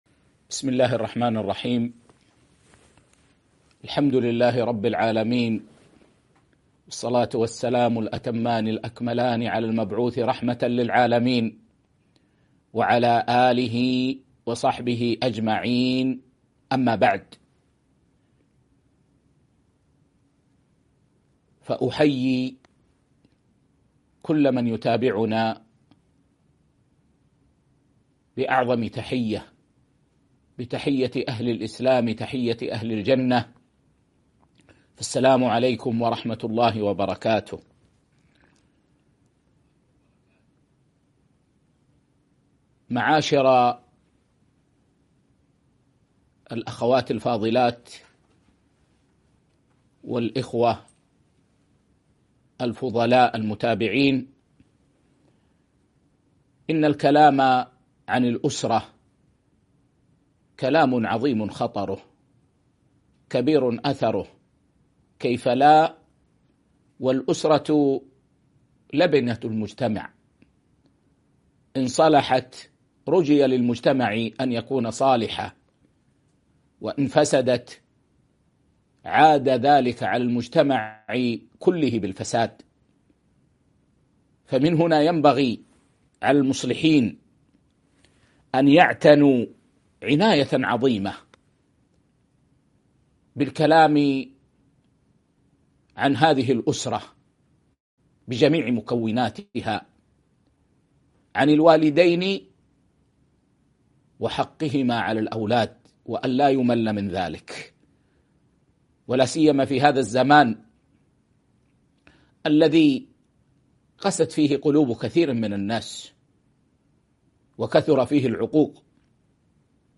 محاضرة - مسؤولية الوالدين تجاه الأبناء